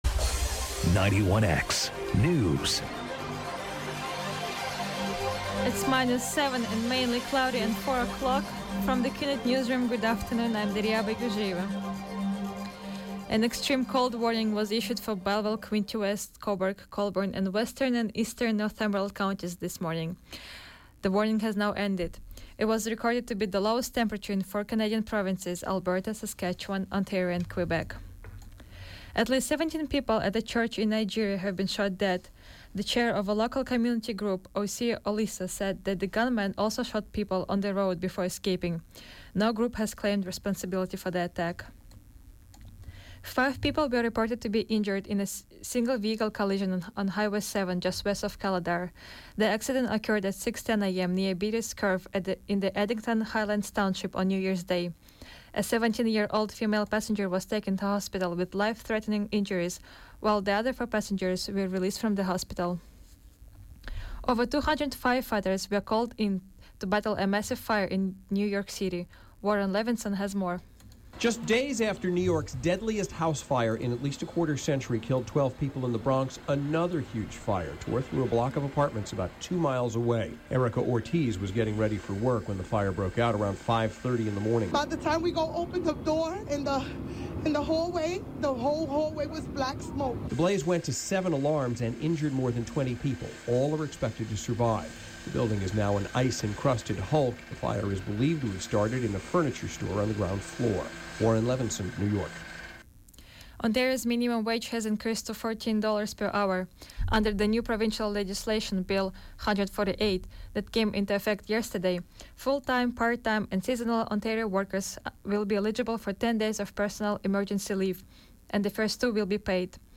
91X Newscast: Tuesday, Jan. 2, 2017, 4 p.m.